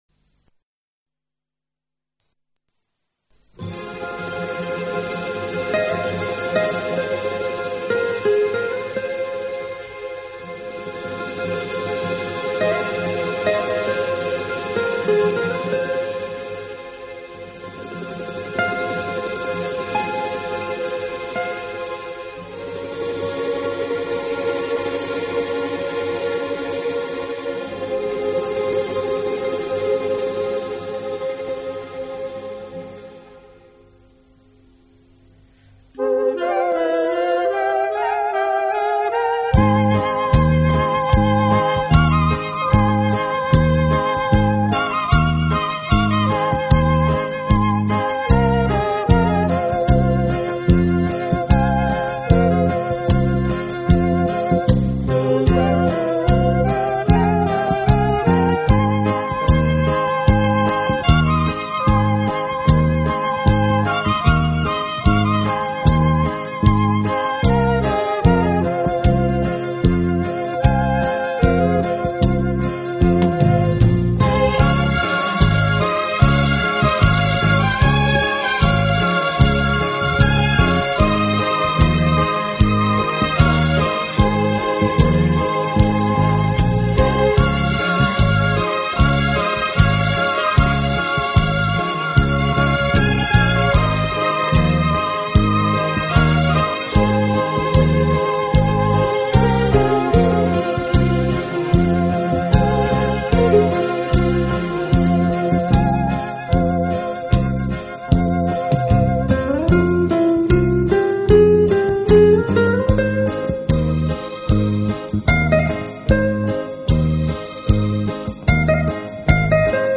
音乐类型：轻音乐